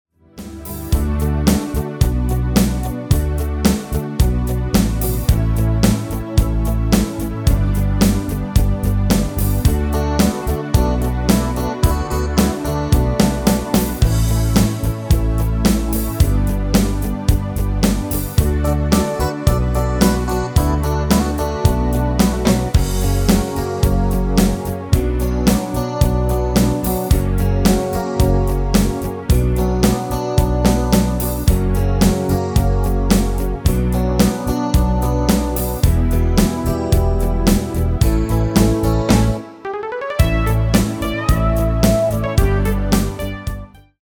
Demo/Koop midifile
Genre: Duitse Schlager
Toonsoort: D
- Vocal harmony tracks
Demo's zijn eigen opnames van onze digitale arrangementen.